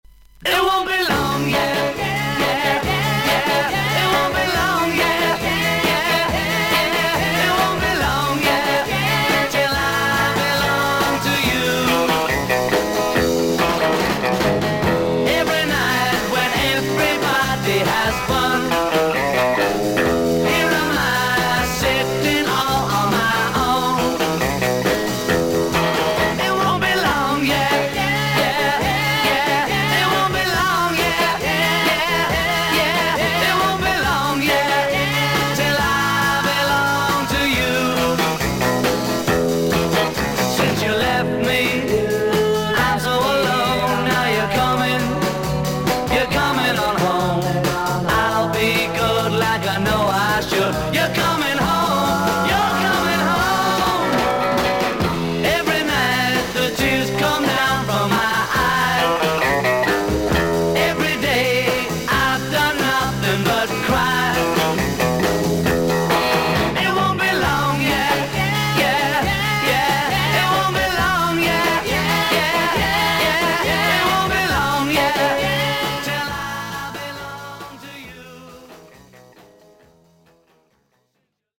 イギリス盤 / 12インチ LP レコード / モノラル盤